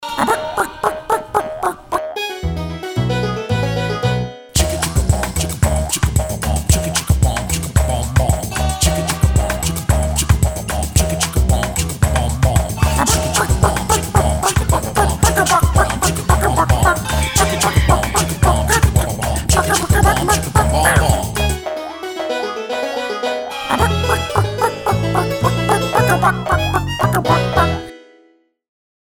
Whimsy, Kids, Retro and Holiday